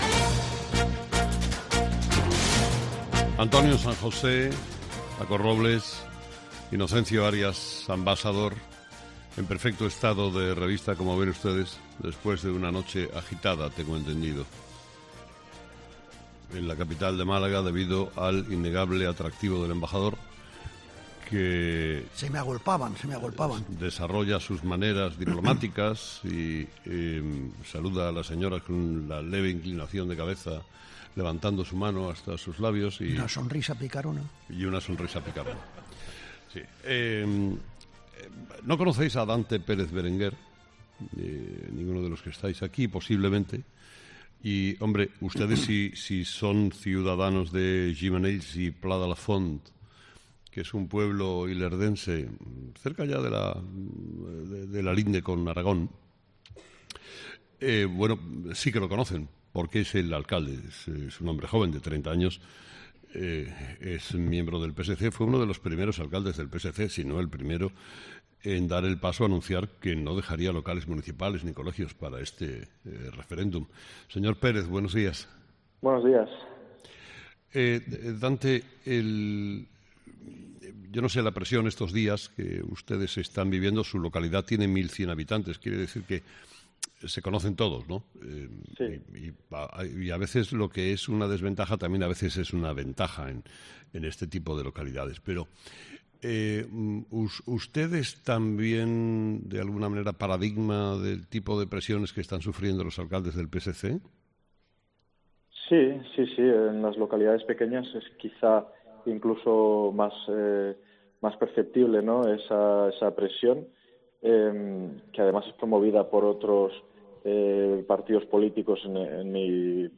El alcalde de Gimenells y Pla de la Font, Dante Pérez